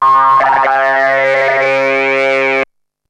E T TALKS 3.wav